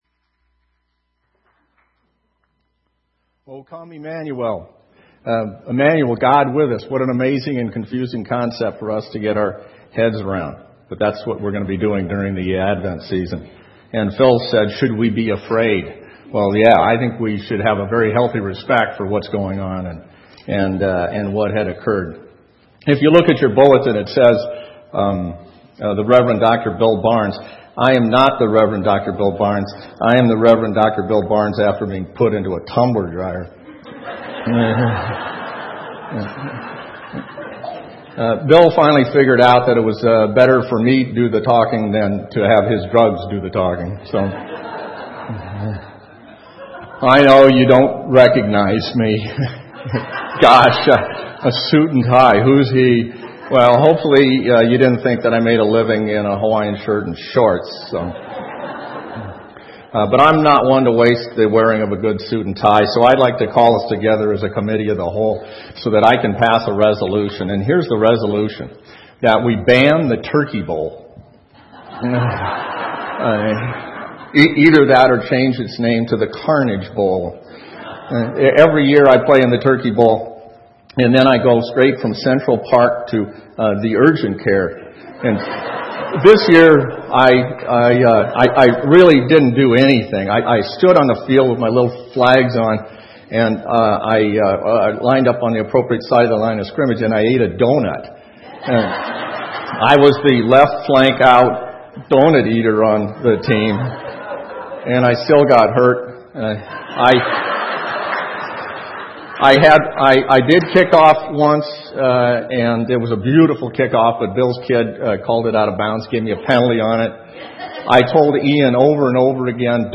Sermon Audio Archives | Church of Newhall